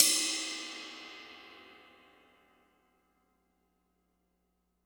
Crashes & Cymbals
Str_Cym1.wav